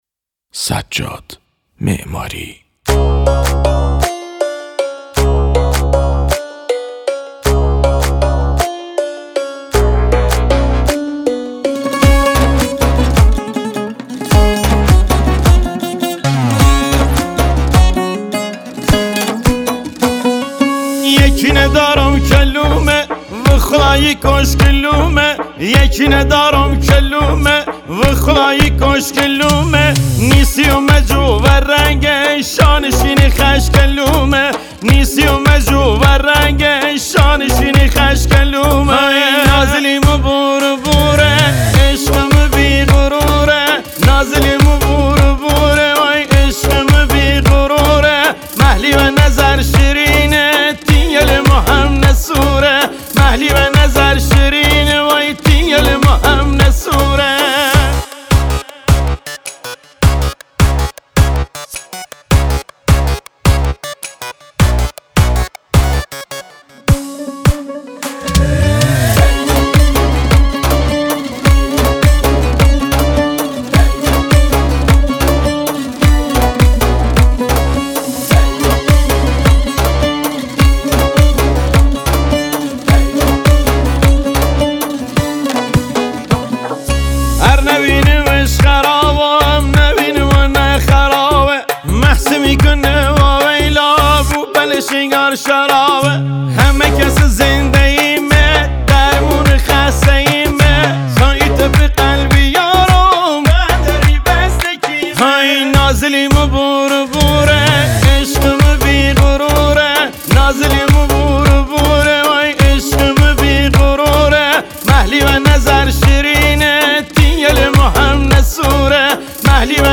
سبک پاپ